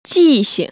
[jì‧xing] 지싱